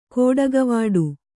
♪ kōḍagavāḍu